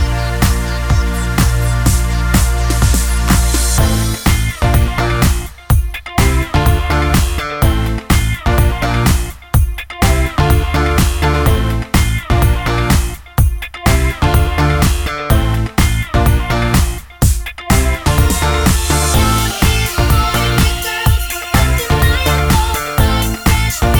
rap section cut Pop (2000s) 3:16 Buy £1.50